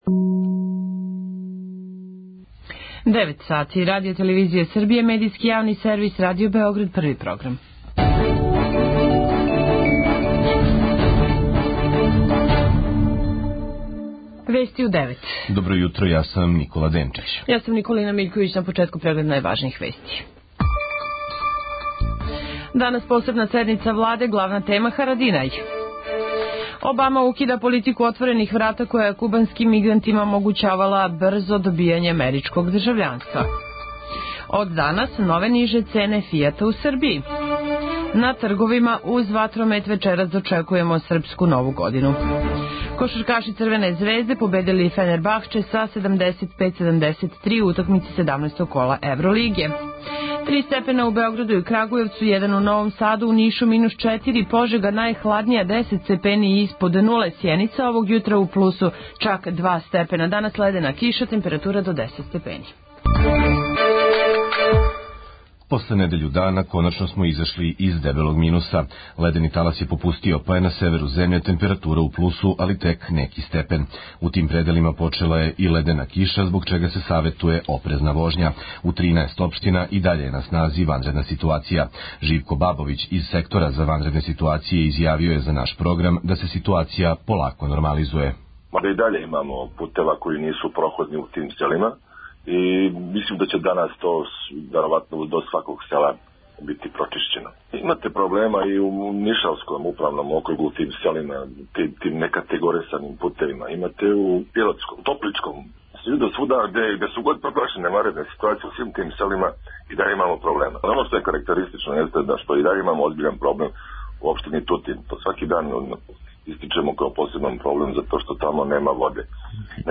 преузми : 2.61 MB Вести у 9 Autor: разни аутори Преглед најважнијиx информација из земље из света.